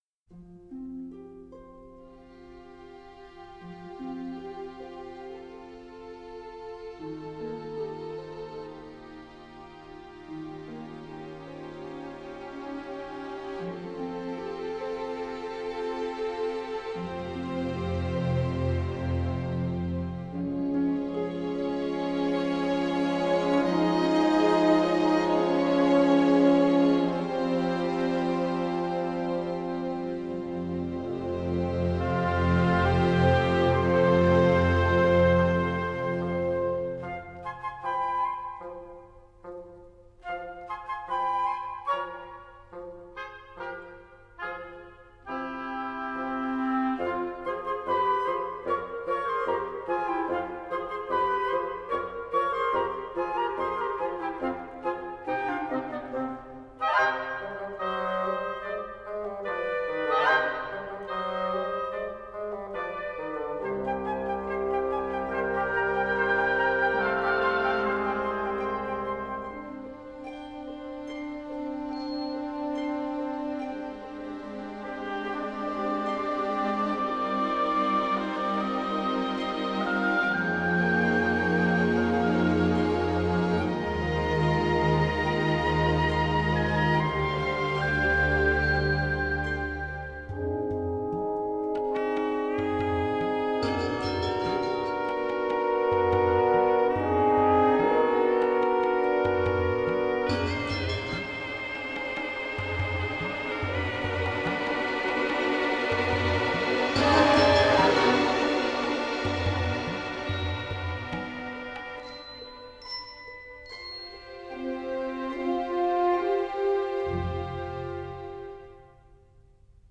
Жанр: Soundtrack